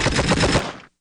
Various MG audio (wav)
machinegun3.wav
machinegun3_115.wav